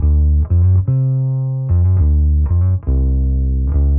Index of /musicradar/dusty-funk-samples/Bass/120bpm
DF_PegBass_120-D.wav